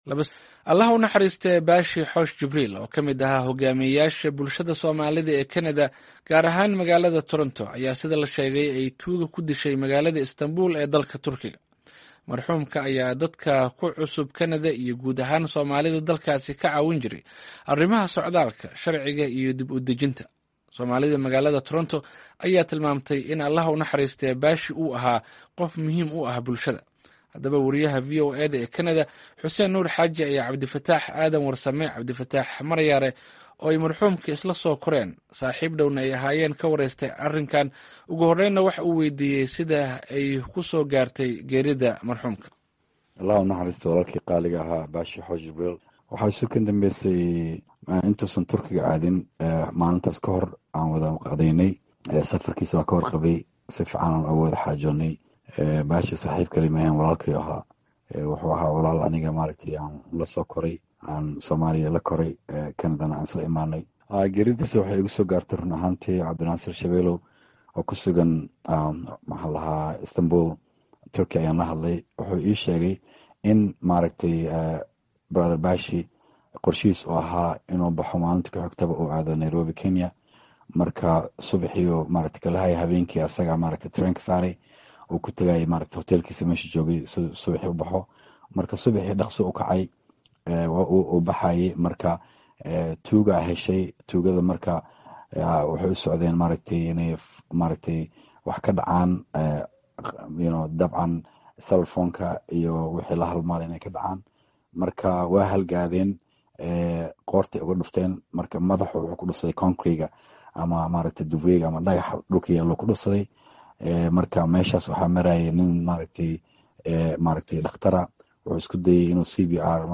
saaxiib dhowna ahaayeen ka wareystay geeridiisa